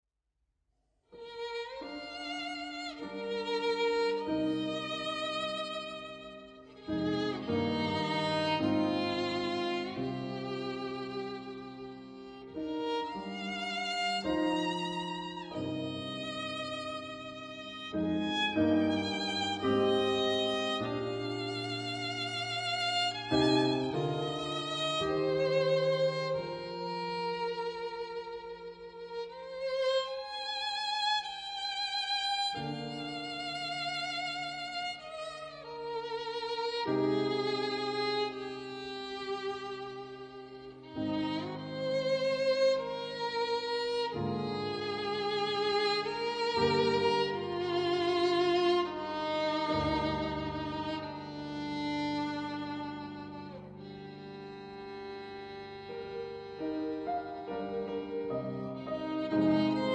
Violine
Klavier
CD-Audio, stereo ·
Aufnahmeort: Franz-Liszt-Konzerthaus Raiding (Österreich)